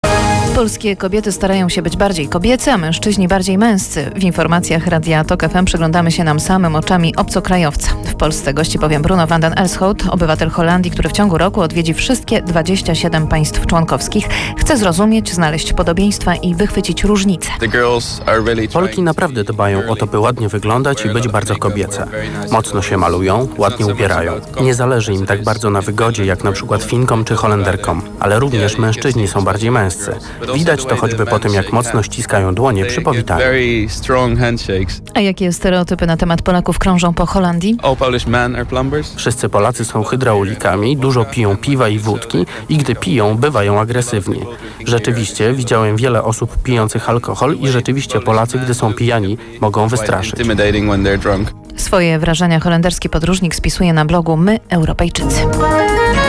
Radio part 2
For anybody who listened to the interview I posted yesterday, there were actually two versions of it although they were based on the same conversation.